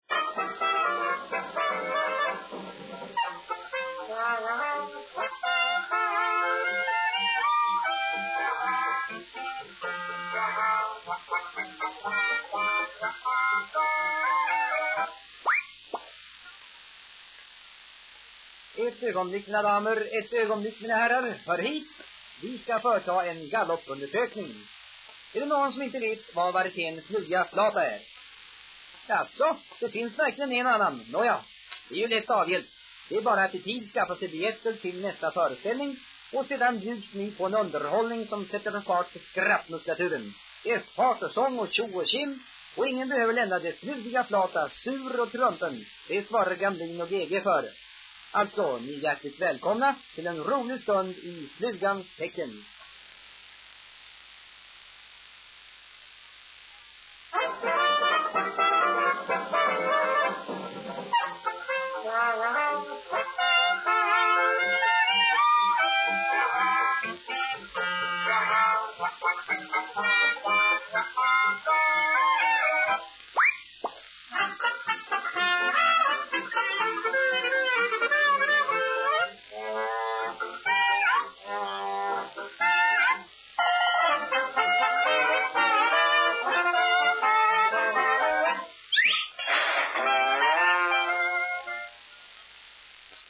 reklamskiva för Flugiga Flata 1949.